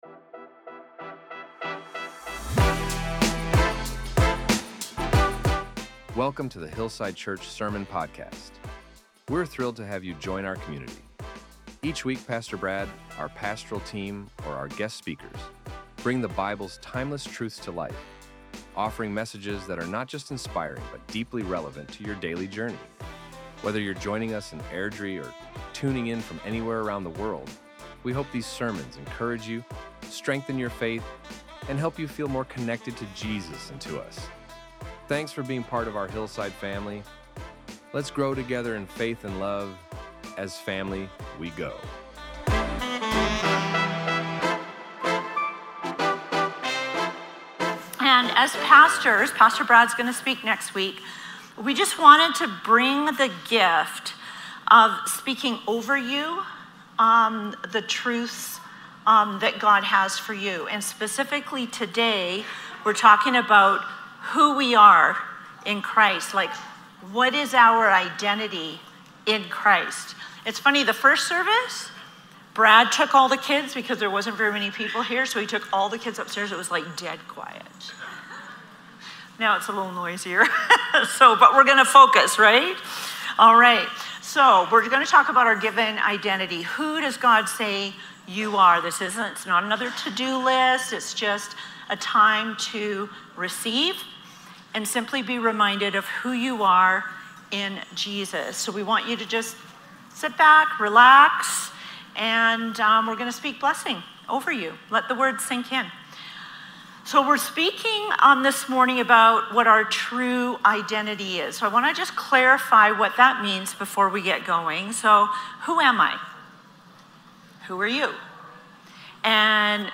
This sermon reminded us that while many of us may know these truths, learning to truly believe and live from them is a lifelong journey.